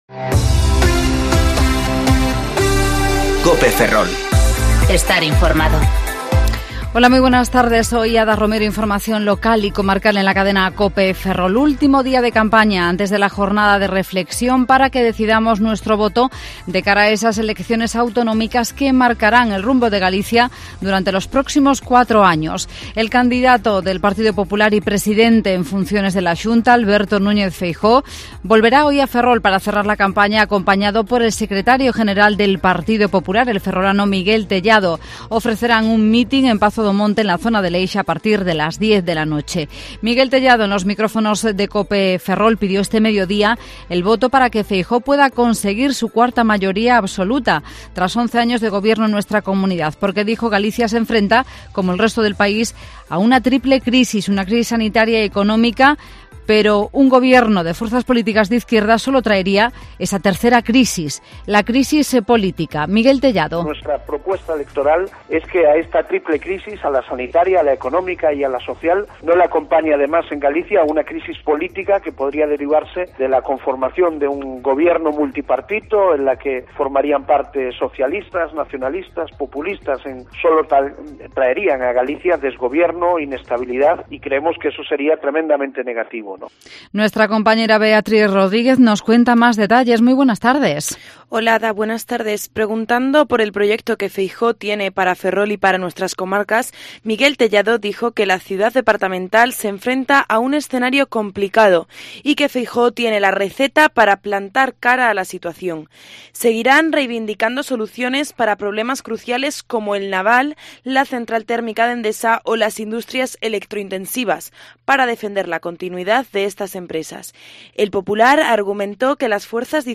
Informativo Mediodía COPE Ferrol 10/07/2020 ( De 14,20 a 14,30 horas)